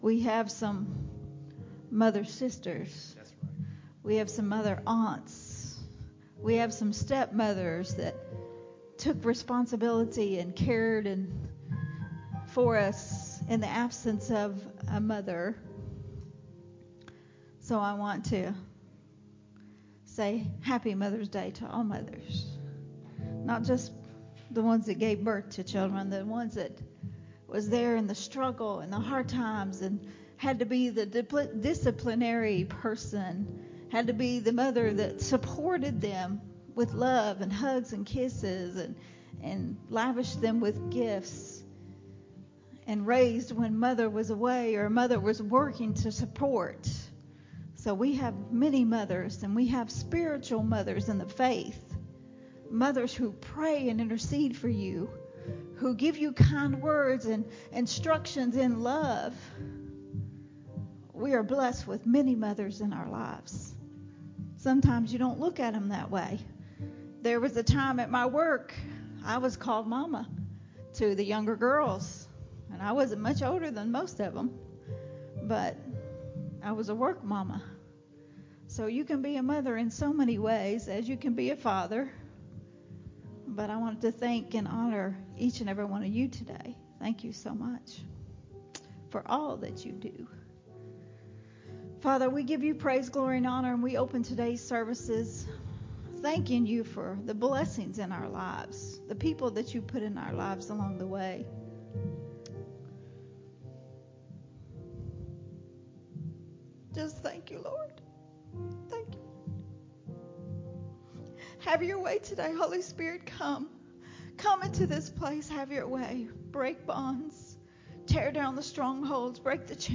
recorded at Unity Worship Center on May 14